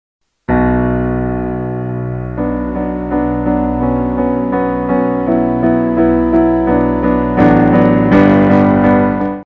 on the Keyboard